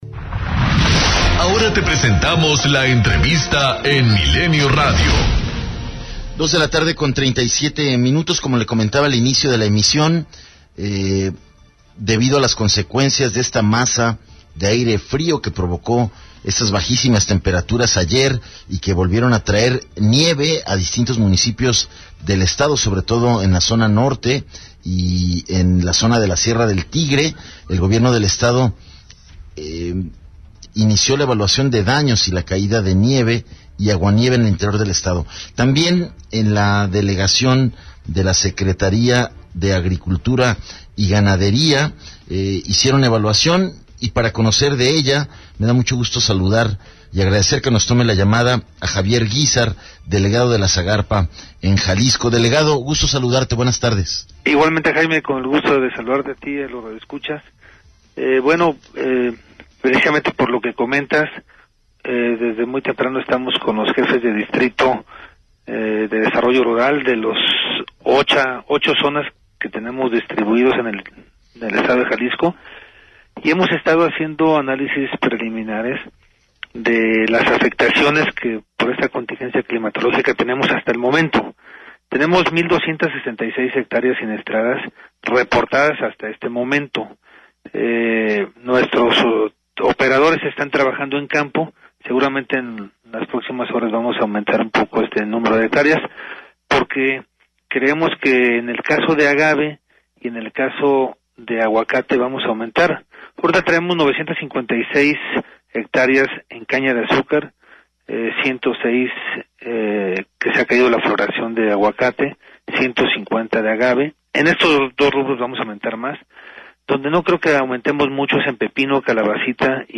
ENTREVISTA 100316
Javier Guizar delegado de la SAGARPA en Jalisco, señaló en entrevista para Milenio Radio que las afectaciones que dejo la contingencia climatológica hasta el momentos son 1266 hectáreas siniestradas, 106 sin floración de aguacate y 150 en agave pero se espere aumente la afectación dijo además que en pepino y calabaza solo son 44 hectáreas que se encontraba en cielo abierto. Se hundieron 4 pangas en Cabo Corrientes con valor de 250 mil pesos pero se espera que la secretaria apoye a los pescadores, dijo además que a nivel nacional se espera apoyo nacional ya que no hay apoyos específicos para es